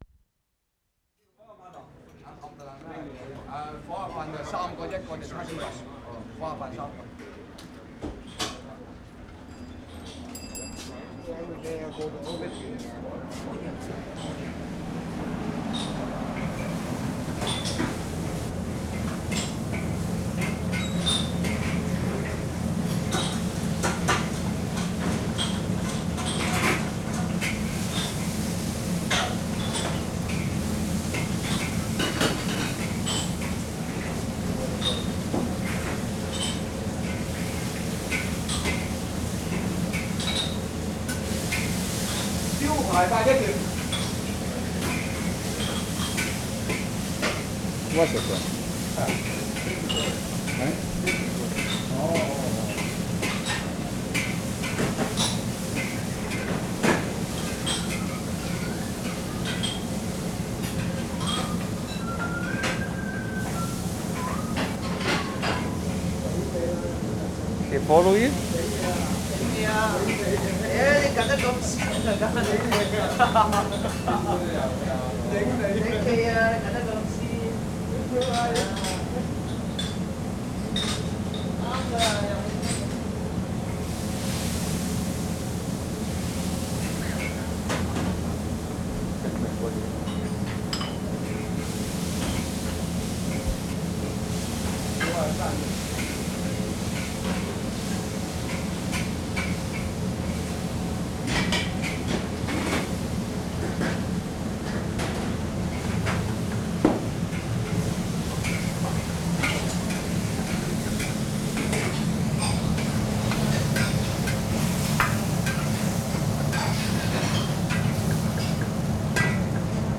DELIGHTFUL FOOD RESTAURANT IN CHINATOWN 6'30"
Takes 7 and 8 were made in Chinatown.
7. Opens in the kitchen. Recordist standing next to a large wok where several things were getting cooked together. Loud ventilator noise above stove.
4'30" walking to different points in the kitchen.
5'30" leaving kitchen into dining area.
5'50" cashier making change.